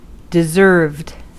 Ääntäminen
Ääntäminen US Haettu sana löytyi näillä lähdekielillä: englanti Käännös Adjektiivit 1. verdient Deserved on sanan deserve partisiipin perfekti.